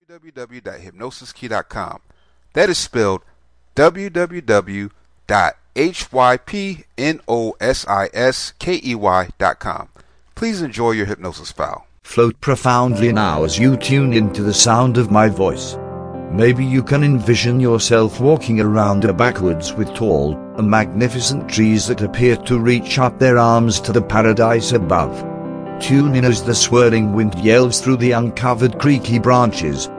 Welcome to parental concern sick child Self Hypnosis, this is a hypnosis mp3 that helps you.